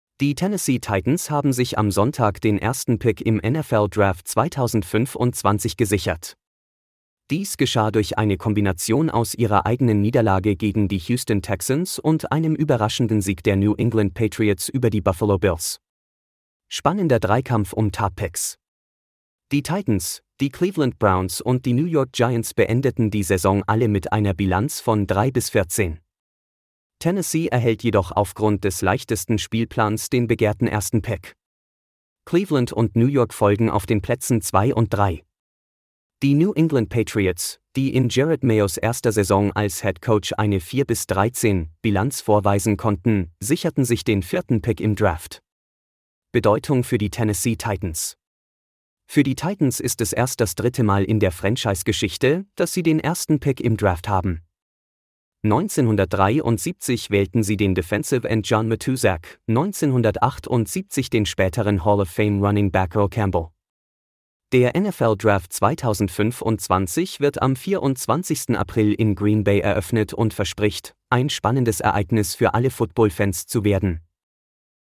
Diese Audioversion des Artikels wurde künstlich erzeugt und wird stetig weiterentwickelt.